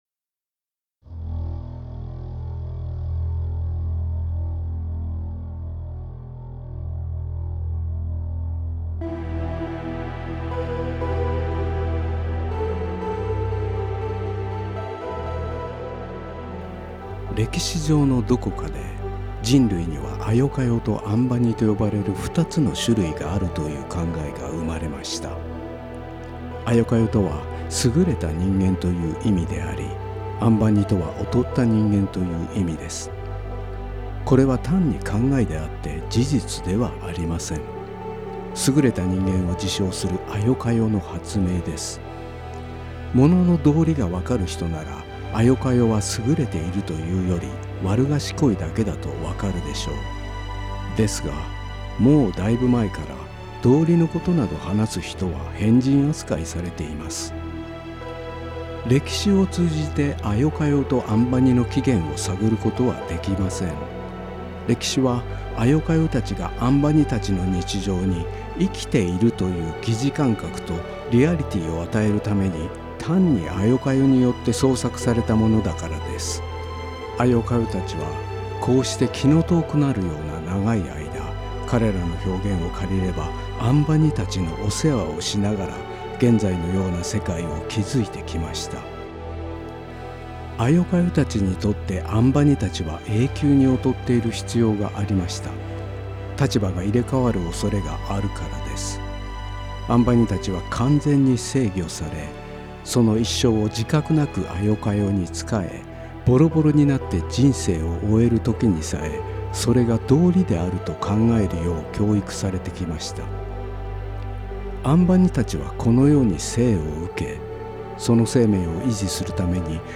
Release type: Single - Spokenword